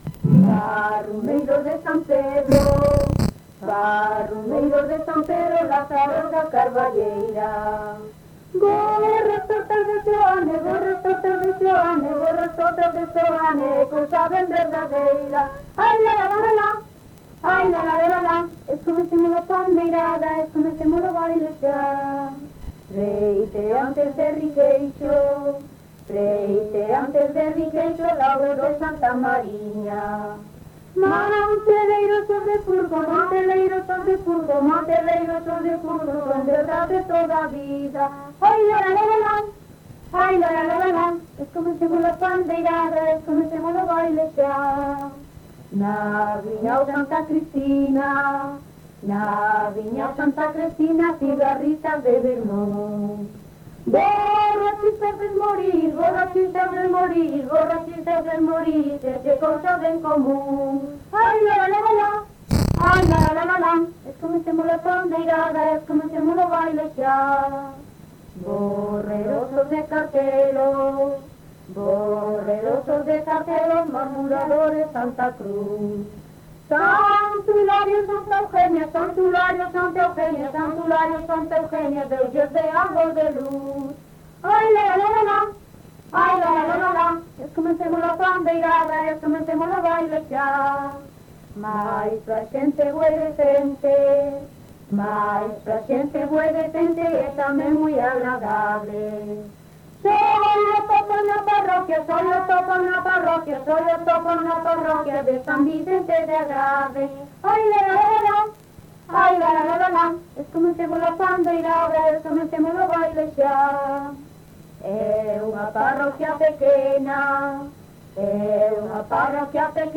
Tipo de rexistro: Musical
LITERATURA E DITOS POPULARES > Coplas
Lugar de compilación: Chantada - A Grade (San Vicente) - Quintá
Datos musicais Refrán